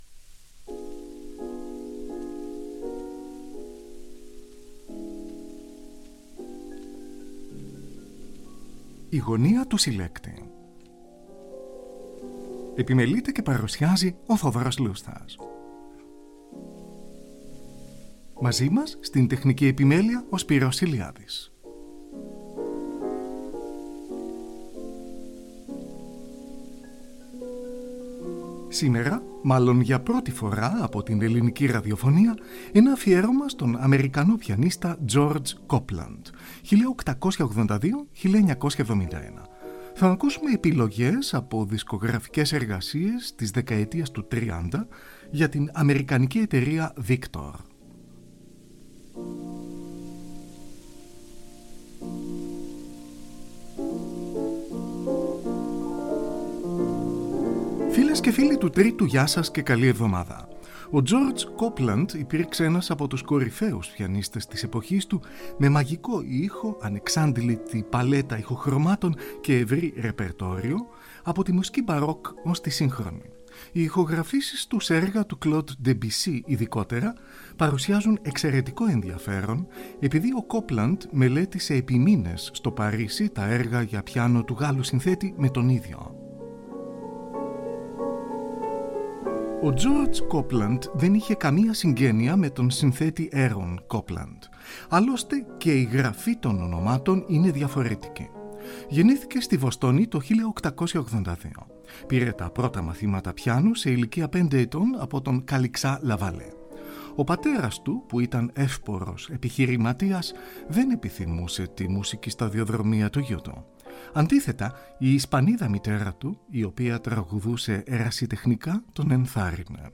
ΑΦΙΕΡΩΜΑ ΣΤΟΝ ΑΜΕΡΙΚΑΝΟ ΠΙΑΝΙΣΤΑ GEORGE COPELAND (1882-1971).
Εκτός από τη μουσική του Γάλλου συνθέτη, ο Copeland ειδικευόταν στην ιβηρική και λατινοαμερικανική μουσική, παίζοντας συχνά έργα των Isaac Albéniz, Enrique Granados, Manuel de Falla, Joaquin Turina, Heitor Villa-Lobos και άλλων.